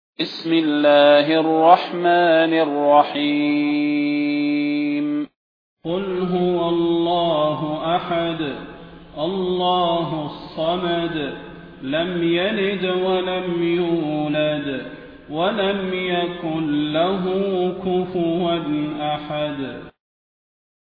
المكان: المسجد النبوي الشيخ: فضيلة الشيخ د. صلاح بن محمد البدير فضيلة الشيخ د. صلاح بن محمد البدير الإخلاص